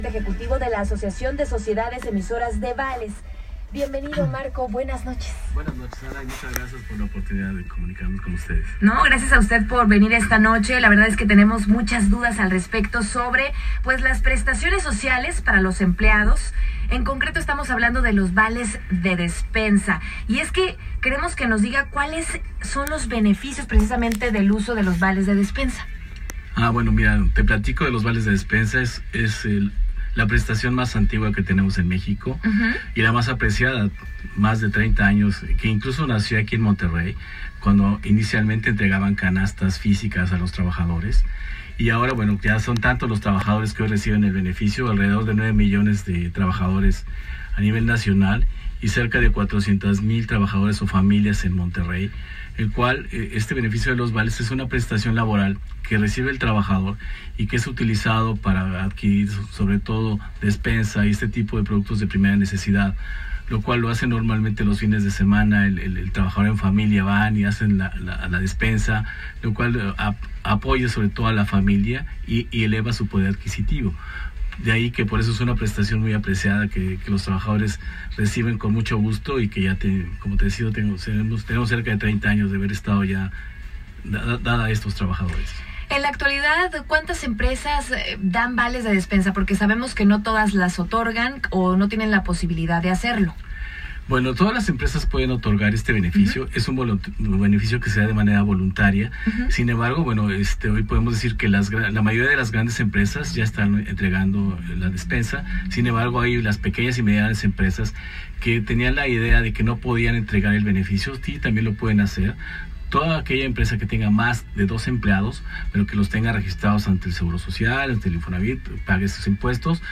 Entrevista en "Imagen" 3 de Octubre 2019 - ASEVAL